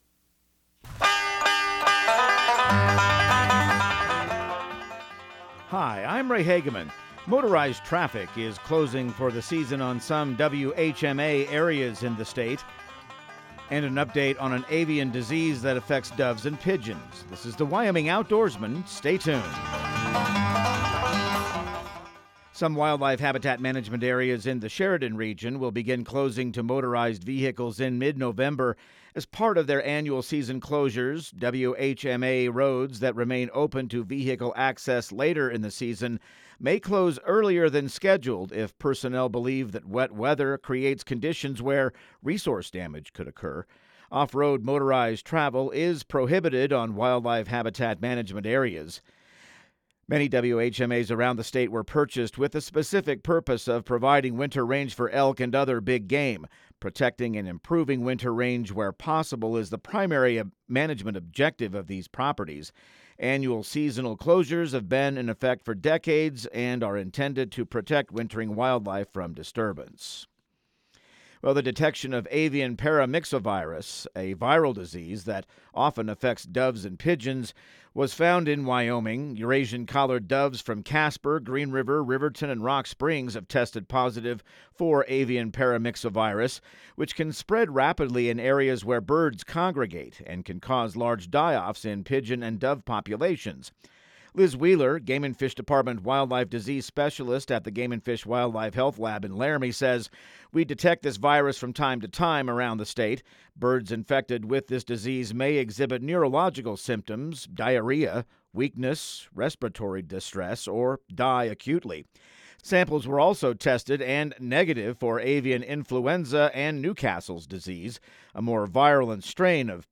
Radio news | Week of November 10